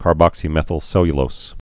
(kär-bŏksē-mĕthəl-sĕlyə-lōs)